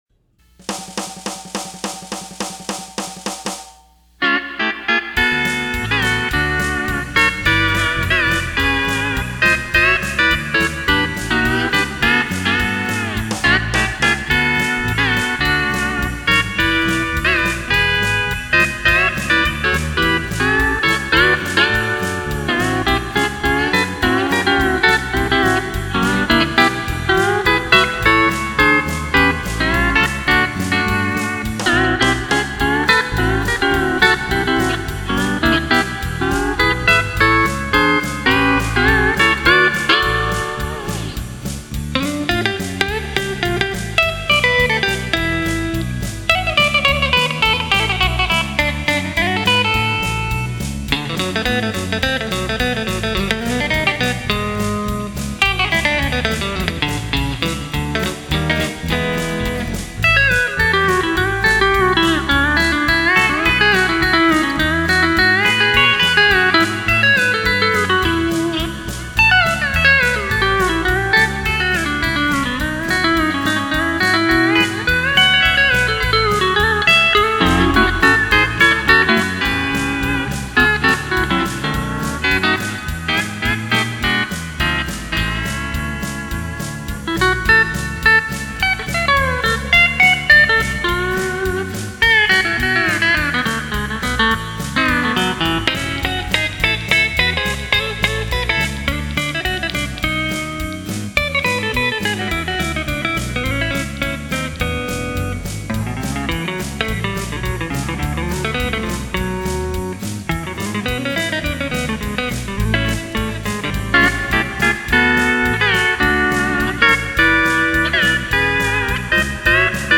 Sounded pretty good to me.